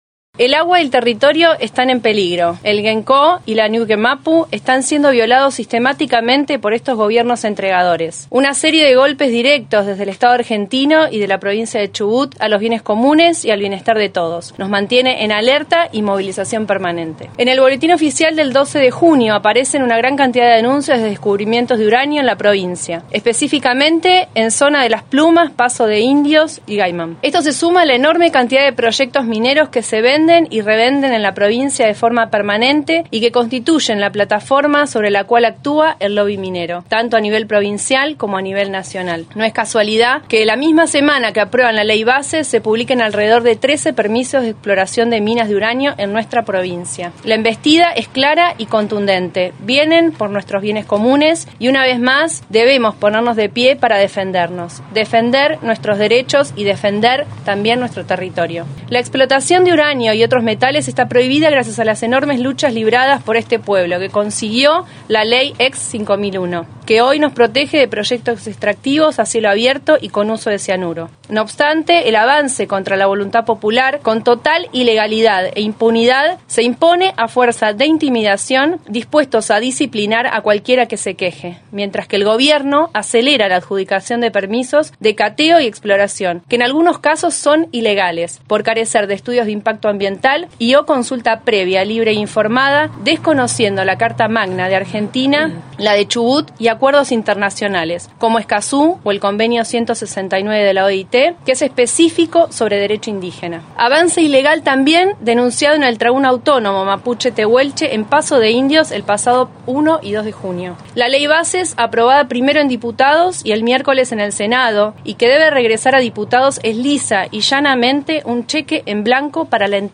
Desde la Unión de Asambleas de Comunidades Chubutenses se emitió un comunicado ante la embestida minera que significa la Ley de Bases y el Rigi (Régimen de Incentivos para Grandes Inversiones). En diferentes comunidades de Chubut como Rawson, Trelew, Puerto Madryn, Gaiman, Dolavon, Puerto Pirámides y Esquel, se realizaron conferencias de prensa para dar lectura a un comunicado unificado que es el siguiente.